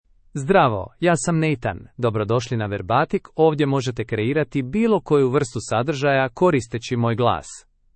MaleCroatian (Croatia)
NathanMale Croatian AI voice
Voice sample
Listen to Nathan's male Croatian voice.
Male
Nathan delivers clear pronunciation with authentic Croatia Croatian intonation, making your content sound professionally produced.